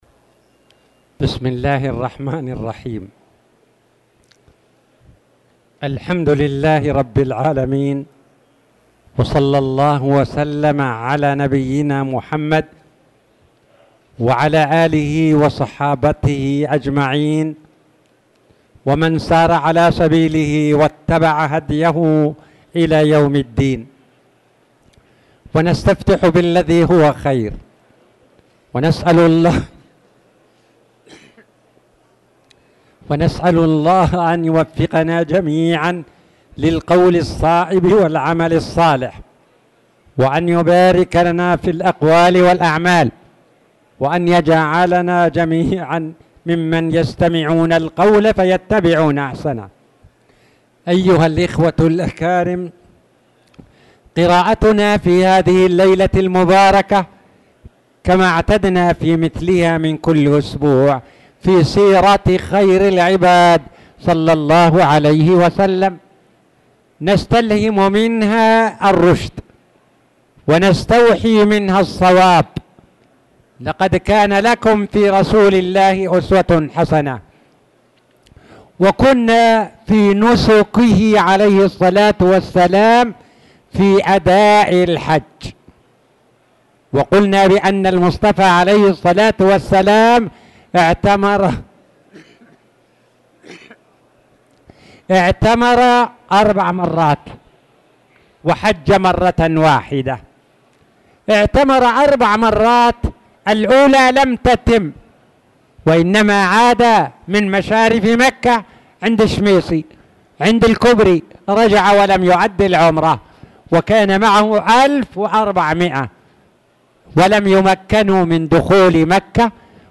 تاريخ النشر ٨ ذو القعدة ١٤٣٧ هـ المكان: المسجد الحرام الشيخ